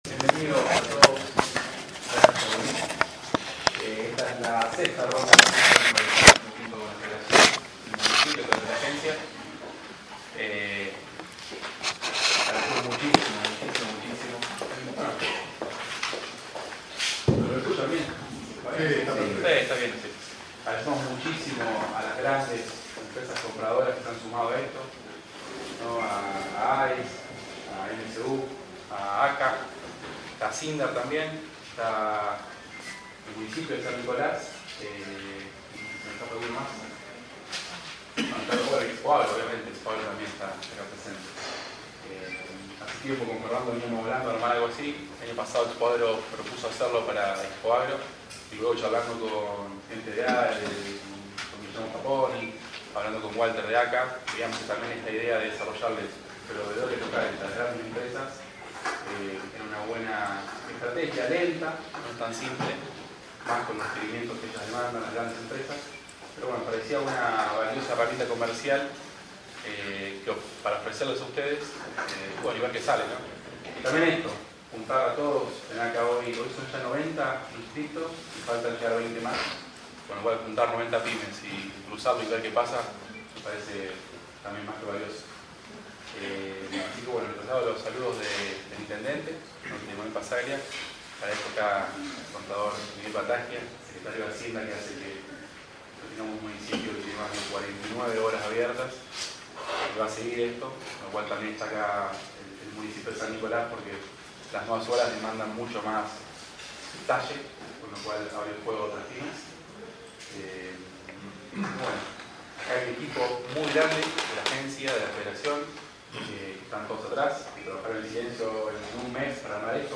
El acto de apertura se realizó hoy jueves 10 de Agosto a las 8.30 hs.
Audio: Apertura palabras